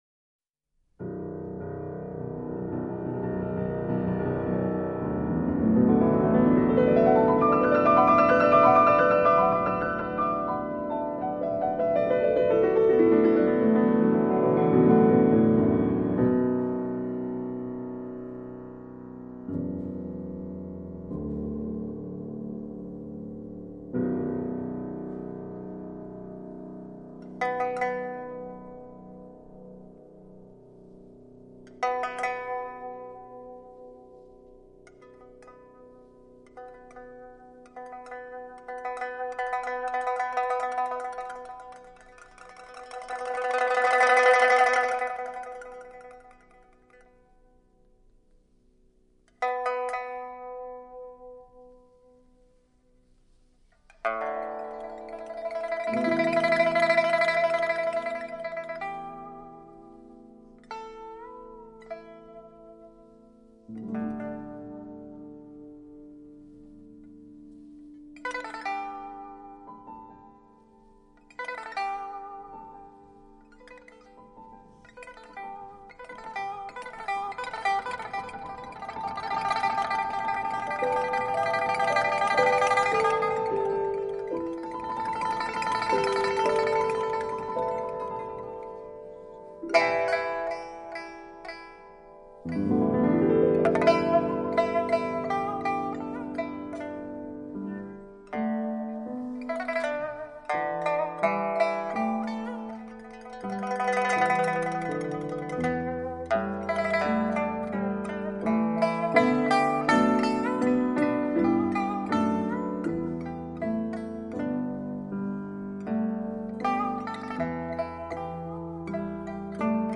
[琵琶＆鋼琴]